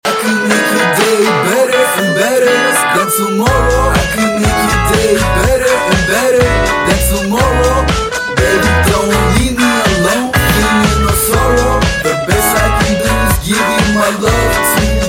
indierock